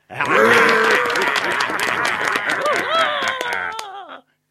Звук аплодисментов пиратов